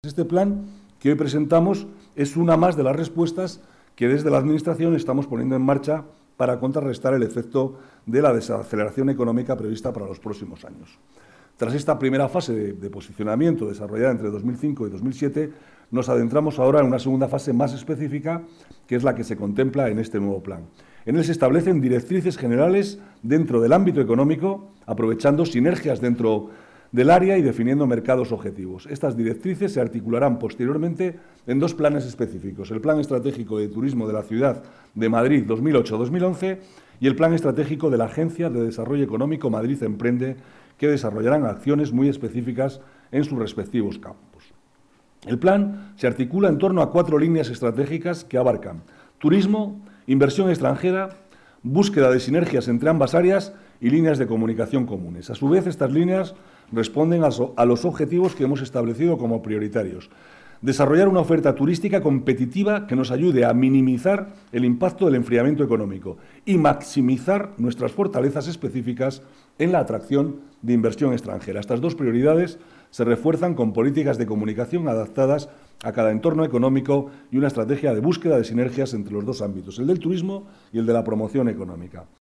Nueva ventana:Declaraciones del vicalcalde, Manuel Cobo, sobre el plan de promoción turística